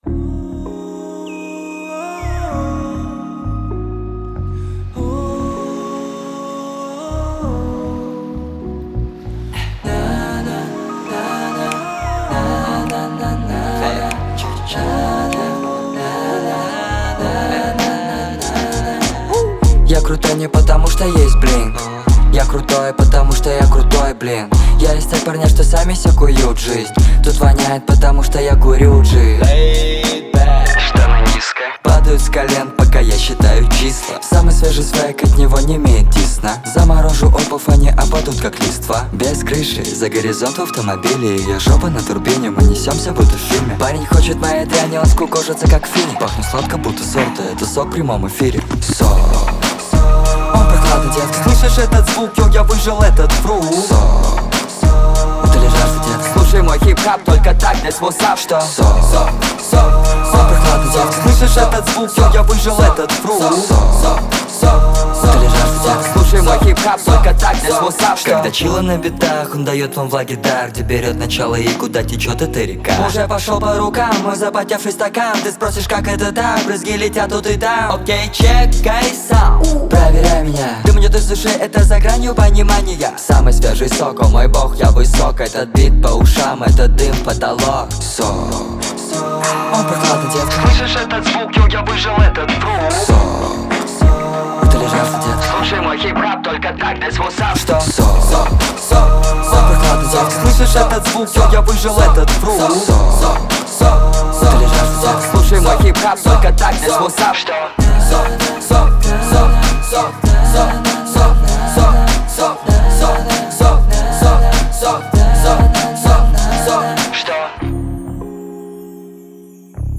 хип-хопа